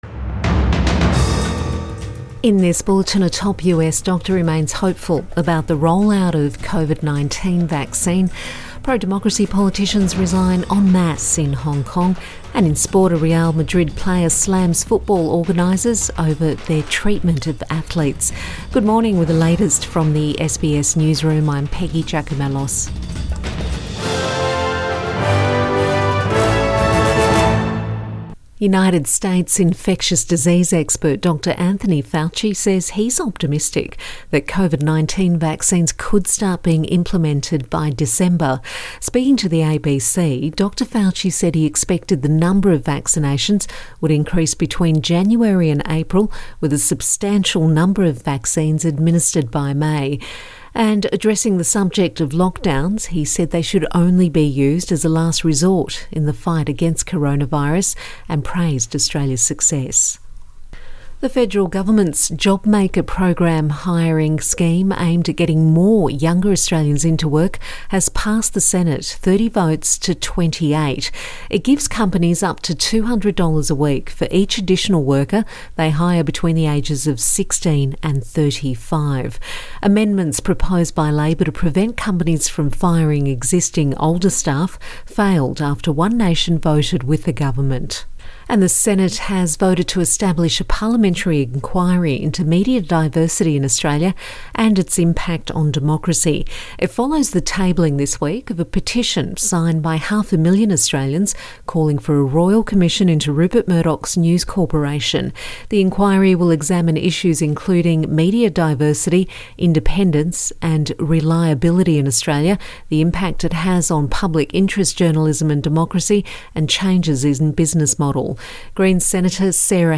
AM bulletin 12 November 2020